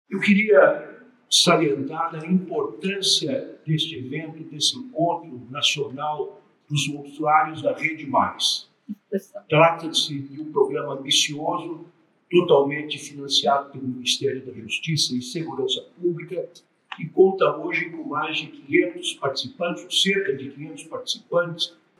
Fala do ministro Ricardo Lewandowski no Encontro Nacional dos Usuários da RedeMAIS.mp3 — Ministério da Justiça e Segurança Pública